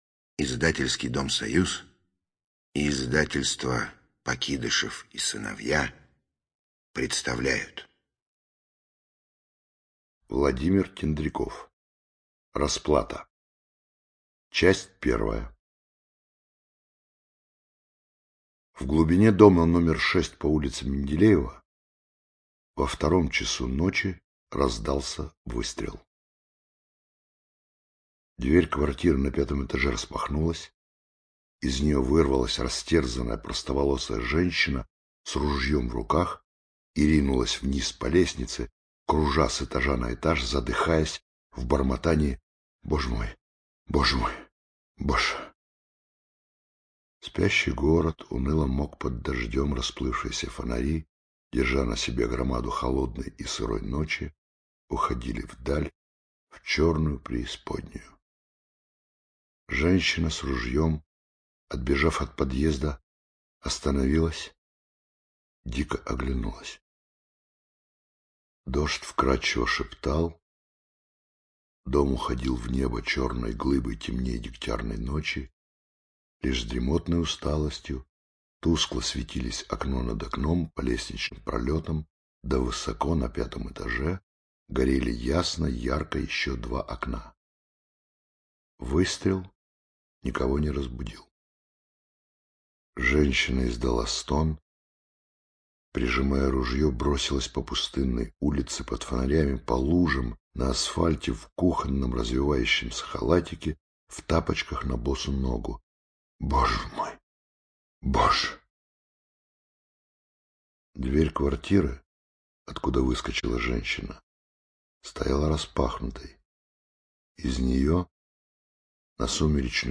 ЧитаетГармаш С.
Тендряков В - Расплата (Гармаш С.)(preview).mp3